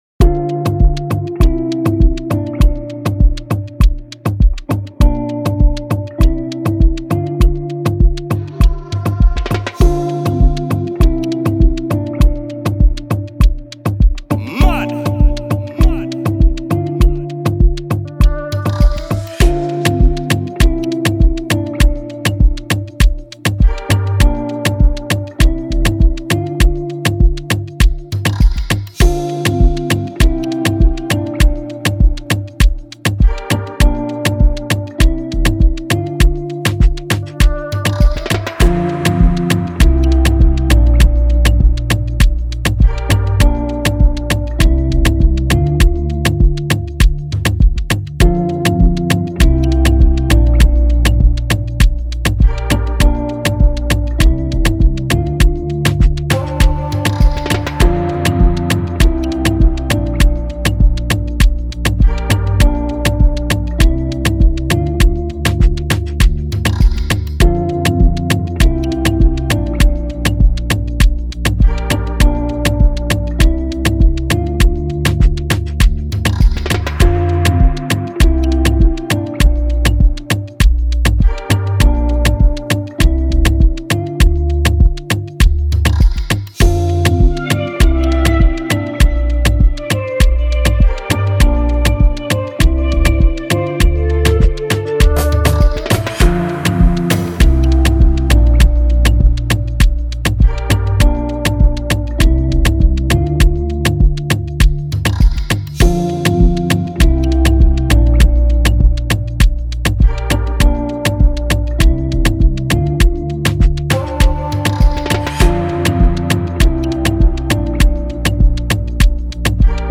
Dancehall / Afrobeats Instrumental